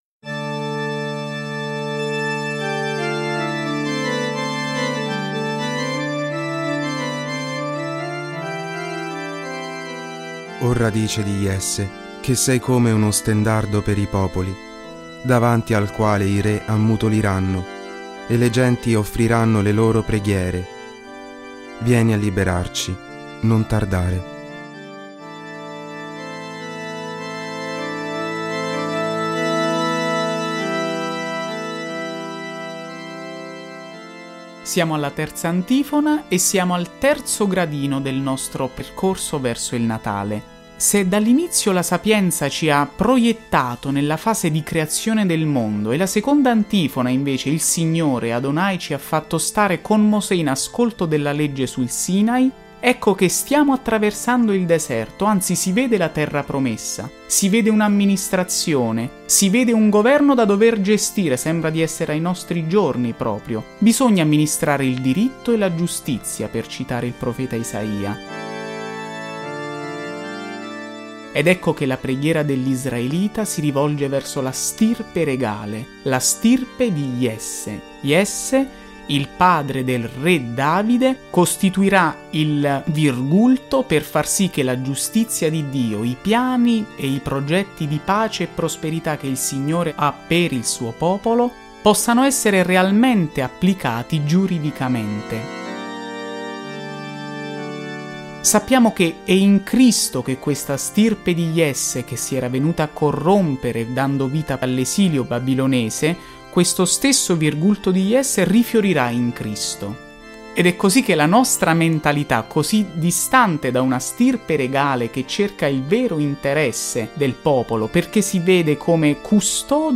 Le esecuzioni delle Antifone O dell'"Ensemble dei Fiorentini"
Dalle antifone maggiori dell’Avvento – O Radix Jesse
O-RADIX-JESSE-CON-EFFETTI.mp3